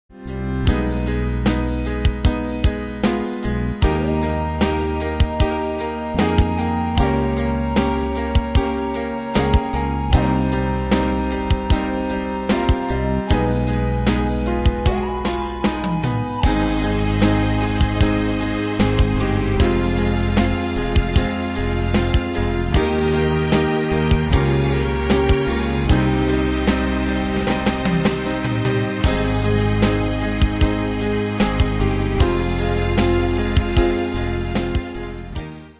Style: PopAnglo Année/Year: 2002 Tempo: 76 Durée/Time: 4.10
Danse/Dance: Ballade Cat Id.
Pro Backing Tracks